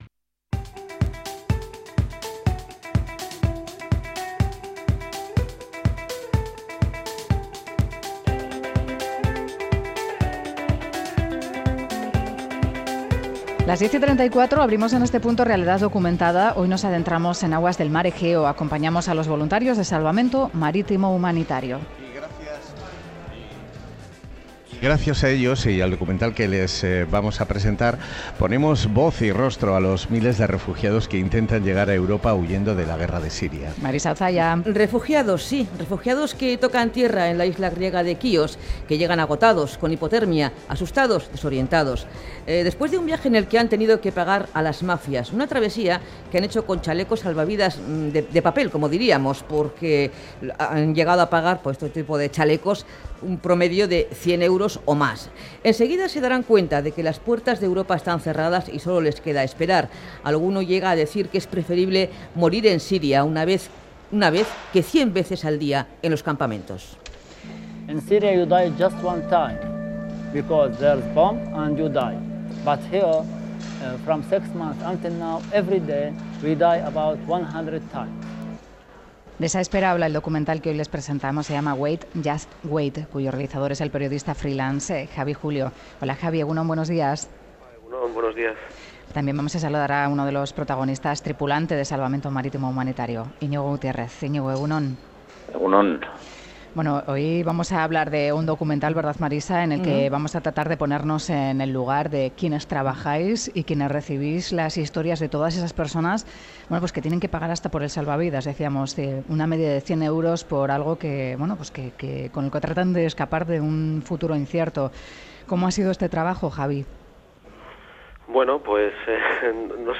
Radio Euskadi BOULEVARD "Wait just wait", el drama diario de los refugiados Última actualización: 28/03/2017 11:21 (UTC+2) Los integrantes del Servicio Marítimo Humanitario son los primeros en ayudar a los refugiados que arriban a la isla griega de Chíos. Este documental recoge su labor Whatsapp Whatsapp twitt telegram Enviar Copiar enlace nahieran